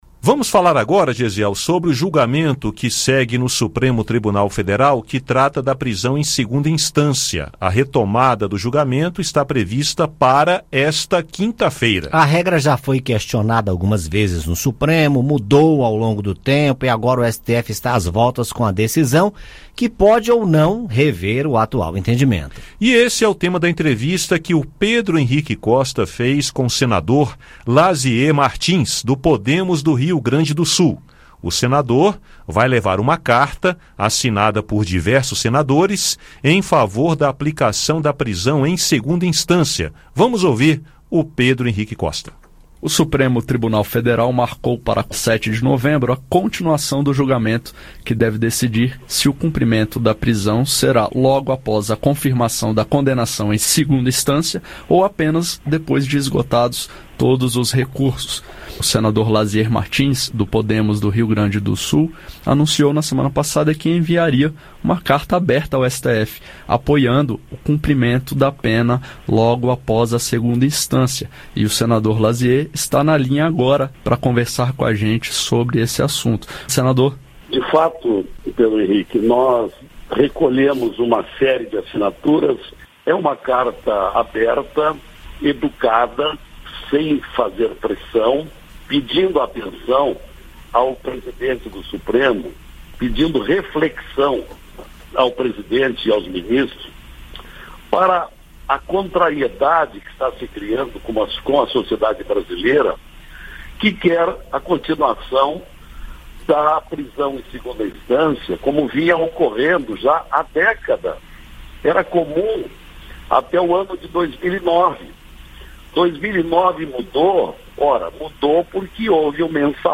Nesta terça-feira (5), conversamos com o senador Lasier Martins (Podemos-RS), que levará ao STF uma carta assinada por diversos senadores em favor da aplicação de prisão após condenação em 2ª instância. Ouça o áudio com a entrevista.